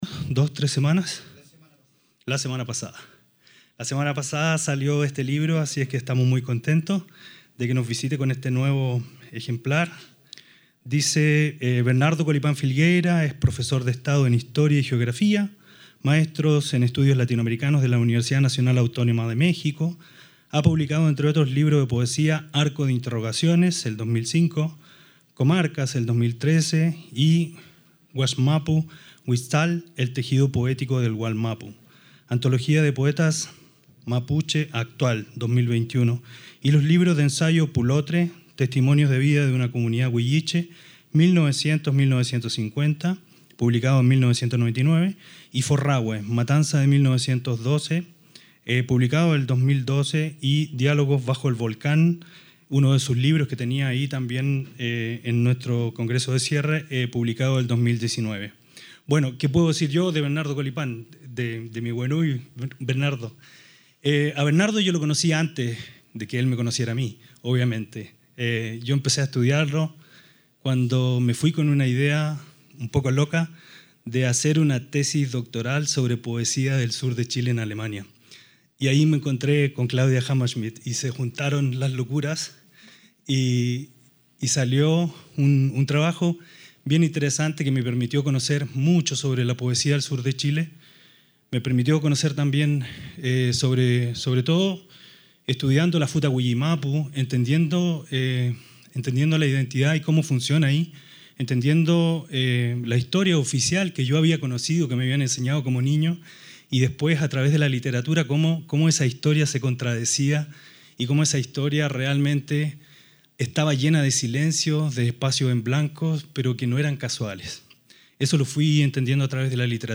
El «Festival Identidades» se celebró del 26 al 27 de noviembre en la ciduad de Guadalajara, México, tras el Congreso de cierre del Laboratorio de conocimientos:Identidades estratégicas y crisis en América Latina. Procesos y tensiones.